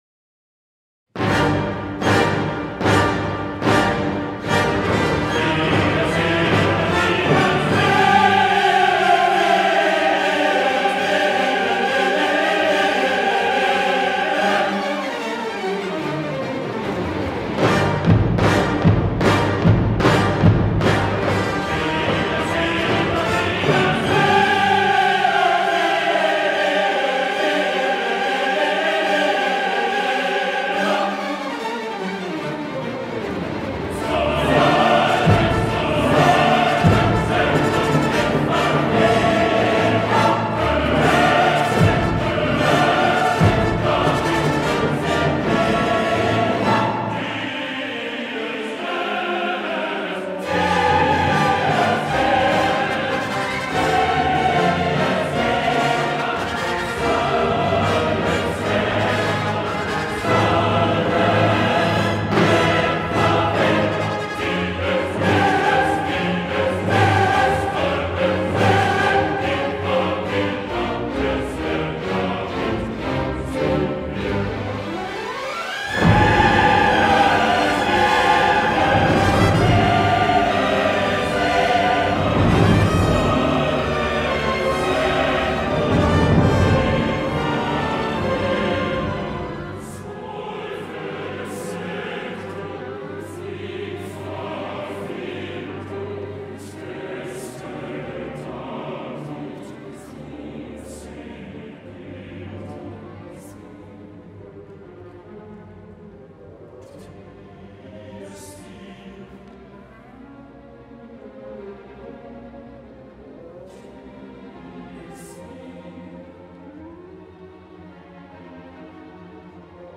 soprano
mezzo-soprano
ténor
basse ; les chœurs
l’orchestre symphonique de la Bayerisches Rundfunks dirigé par Mariss Jansons
Verdi-Messa-da-Requiem-Chor.mp3